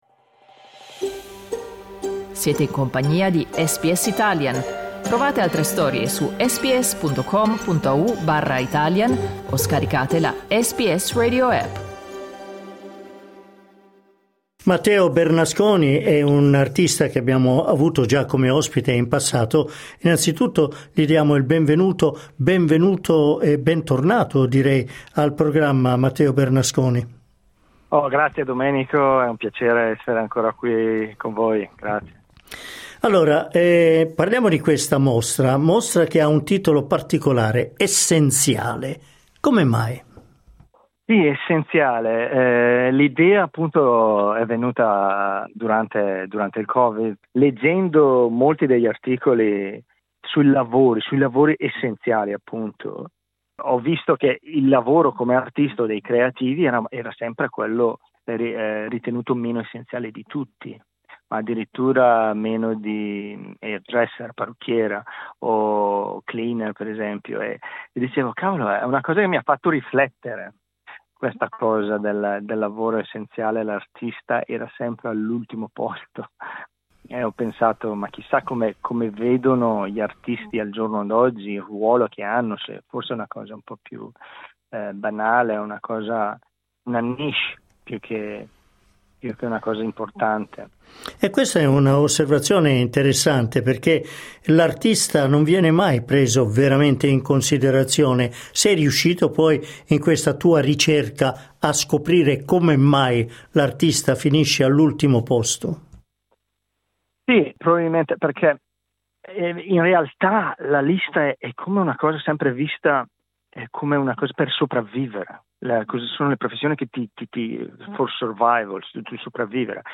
Clicca sul tasto "play" in alto per ascoltare l'intervista integrale Un ritratto dell'attrice Claudia Karvan.